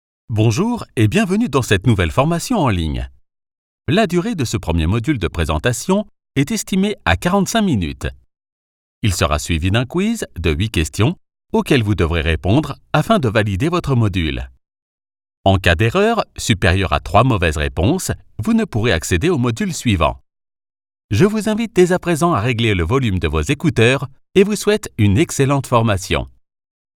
Versatile, Natural, Cool, Deep, Warm
E-learning